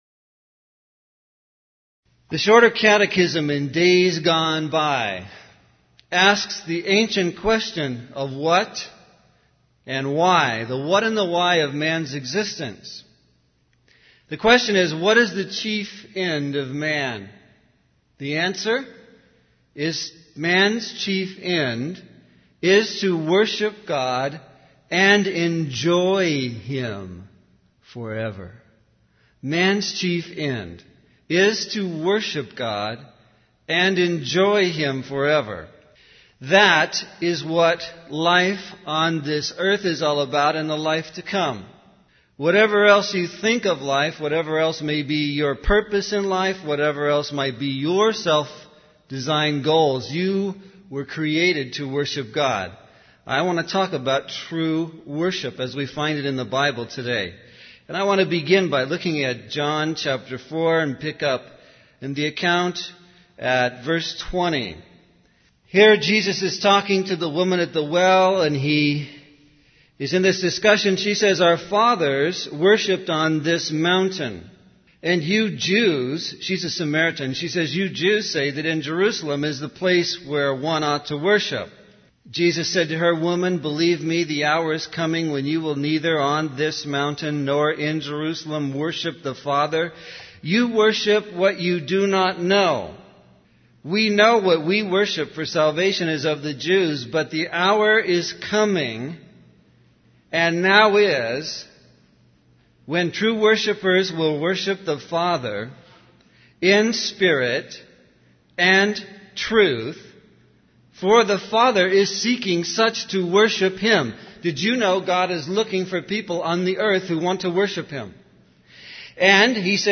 In this sermon, the preacher emphasizes the importance of understanding the grace of God before engaging in worship. He highlights that the first thing to do after comprehending God's grace is to present oneself as a living sacrifice, holy and acceptable to God. The preacher also discusses the consequences of sin and the need for repentance, as well as the true gospel message of God's love and salvation through Jesus Christ.